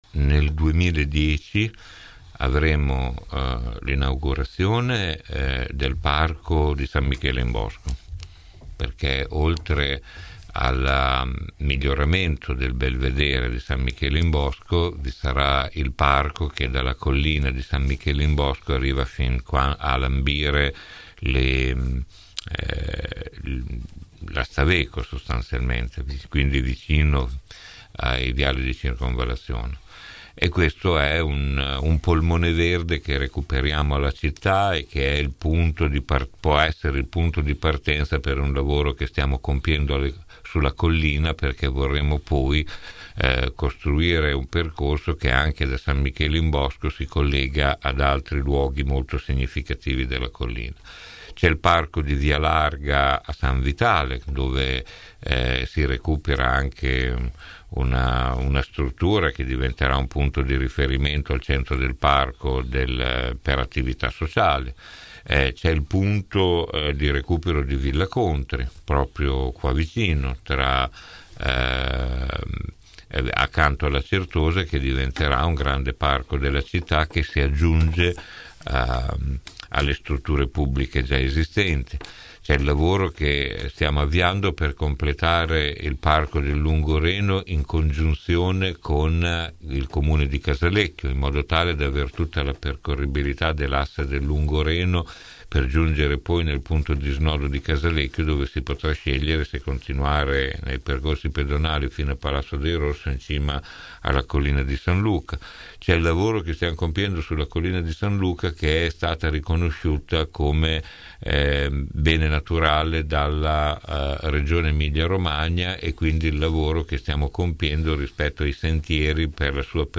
17 nov. – Questa mattina ai nostri microfoni l’assessore all’urbanistica, ambiente e sport del comune di Bologna Maurizio Degli Esposti ha risposto su una serie di punti a cominciare dalla questione dei “23 saggi” chiamati a “riprogettare Bologna” che, afferma l’assessore, non hanno alcun potere decisionale